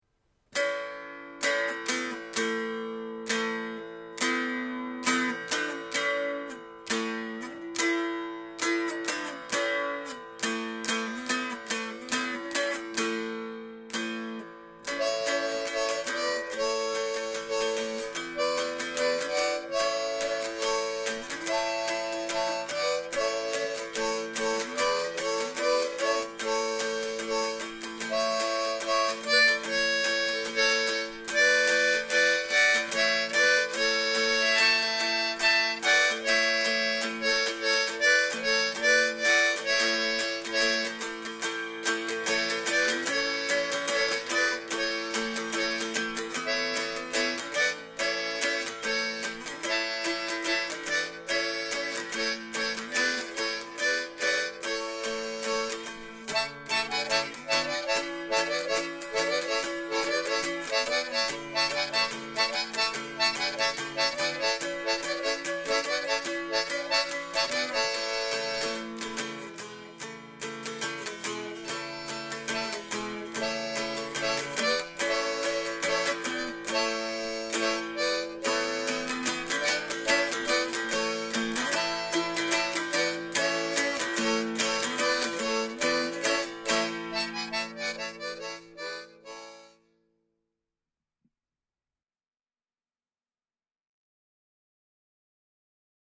I woke up this morning humming “Go Tell Aunt Rhody” and knew I had to try it on the harmonica with a dulcimer accompaniment.
It’s pretty rough.
I found it sounded best with an “A” harmonica.
I did the dulcimer first and then played along with the harmonica in a second track. I edited the volume of each track and panned the dulcimer slightly left and the harmonica slightly right.
This was just a practice session to figure out how to do it.